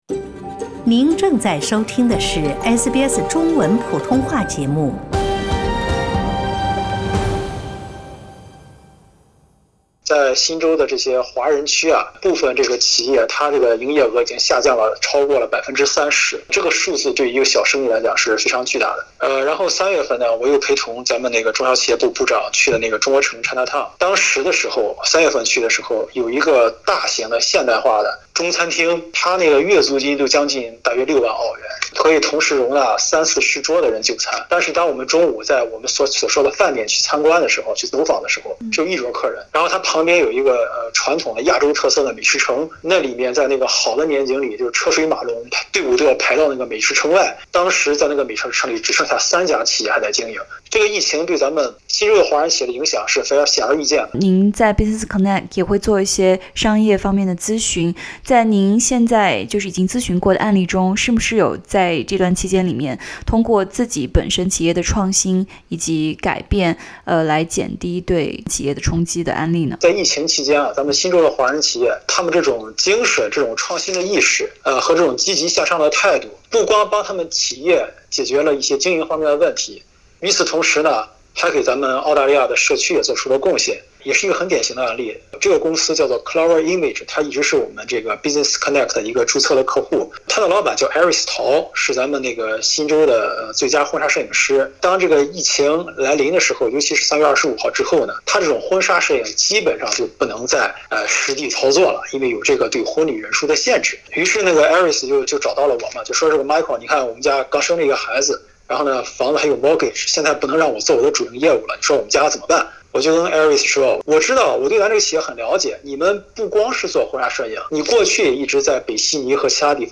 从1月底中国新冠病毒疫情爆发以来，在澳大利亚的华人企业遭受了不同程度的重创。点击图片收听录音采访。